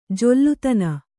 ♪ jollutana